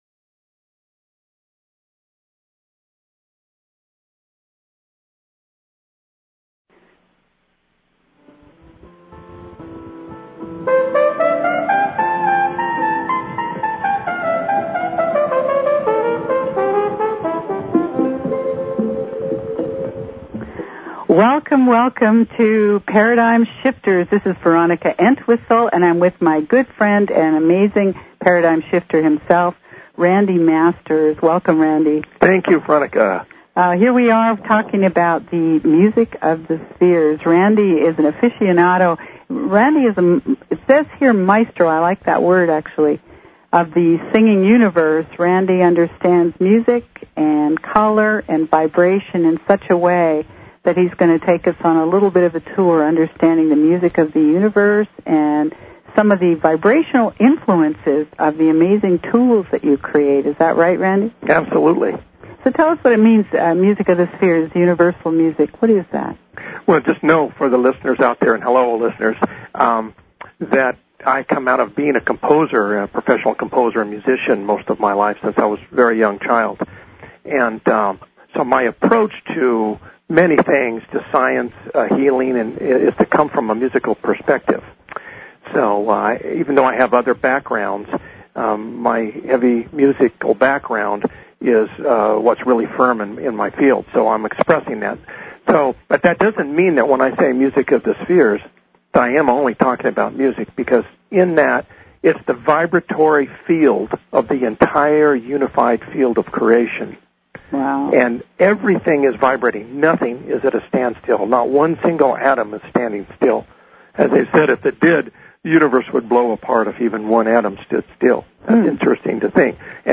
The Debut of Paradigm Shifters talk show, August 28, 2007